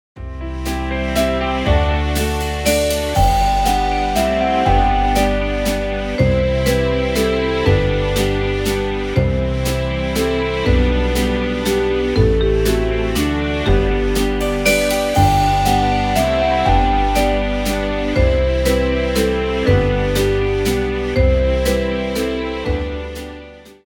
als dezentes Playback
Bandplayback
Pianoplayback